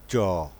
আন্তর্জাতিক ধ্বনিলিপি : cʃ
এটি প্রশস্ত দন্তমূলীয় তালব্য ধ্বনি (Dorso-Alveolar, Palatal), অঘোষ, অল্পপ্রাণ ও স্পর্শ বর্ণ